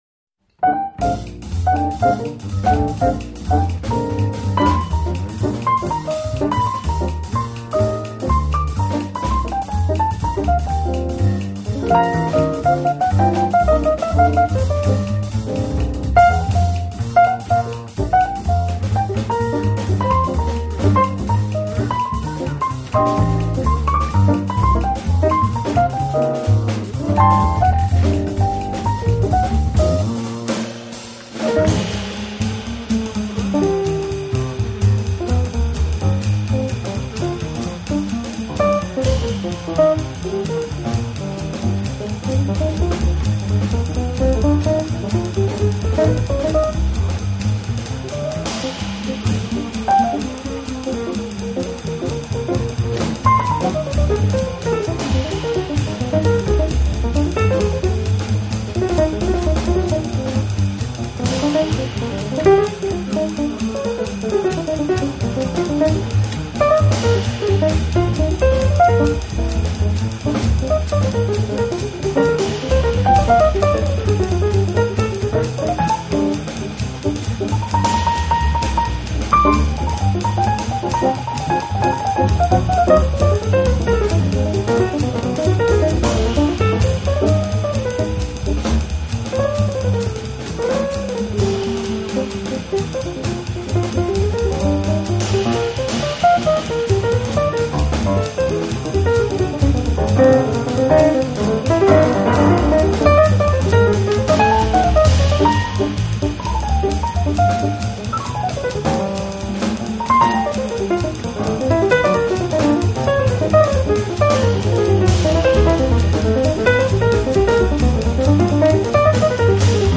爵士三重奏